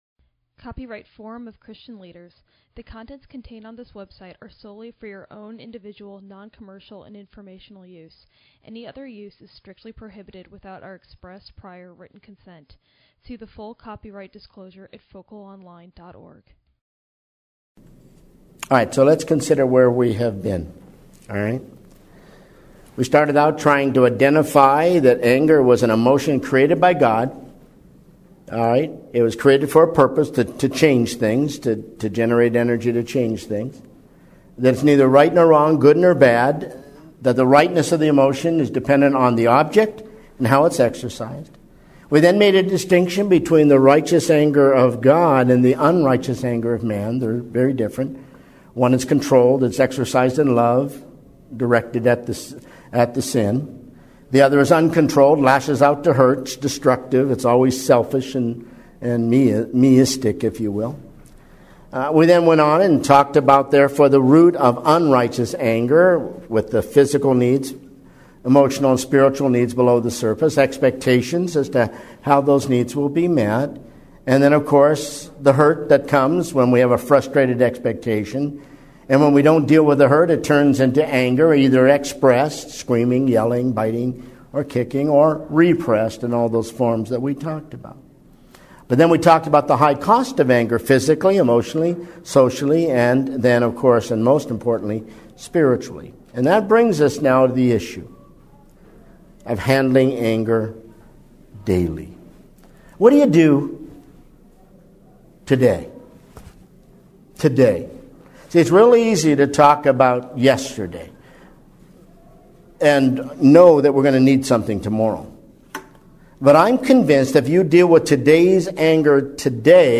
Event: ELF Pre-Forum Seminar